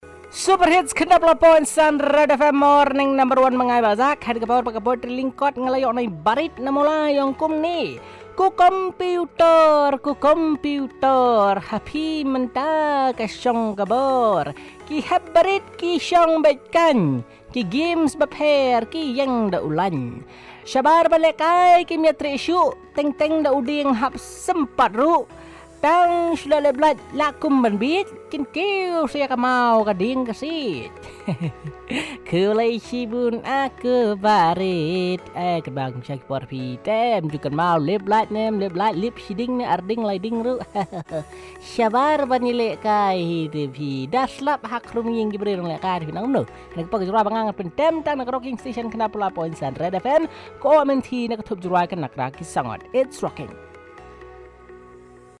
Short Poem on Computer gaming